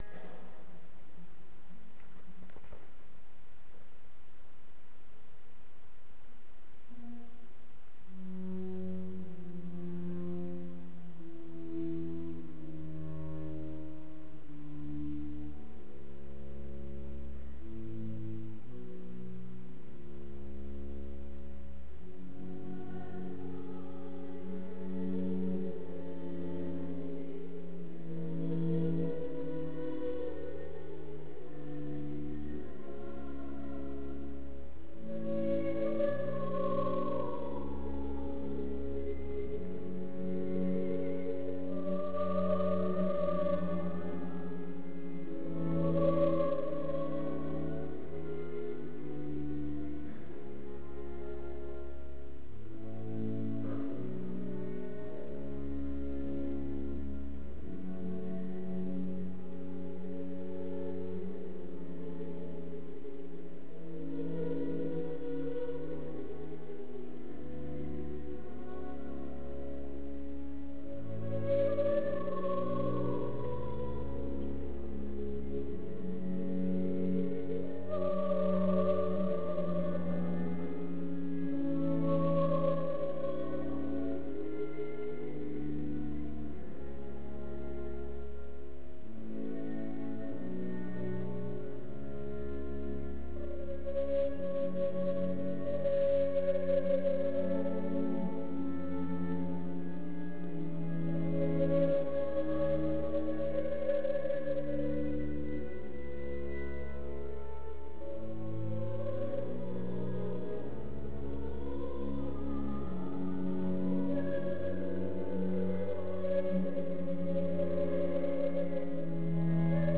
Een audiofragment van het Stabat Mater klassiek concert in de kerk van Doel.